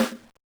drumroll7.ogg